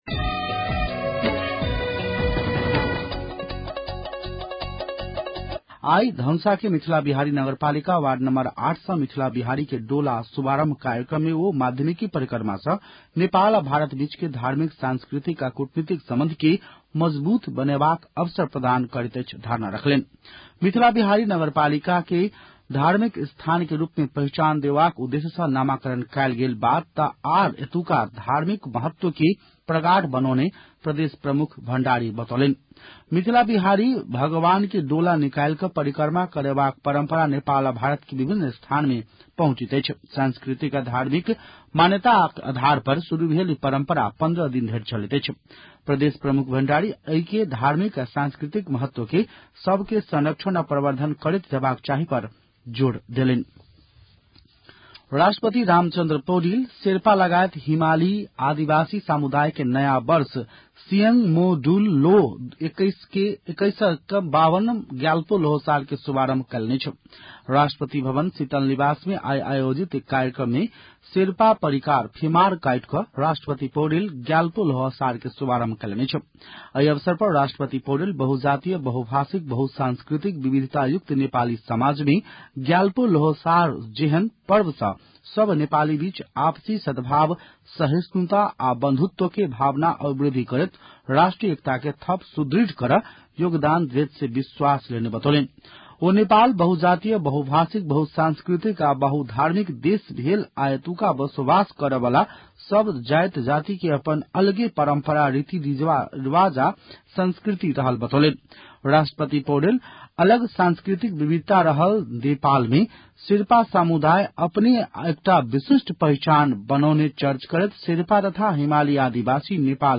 मैथिली भाषामा समाचार : १६ फागुन , २०८१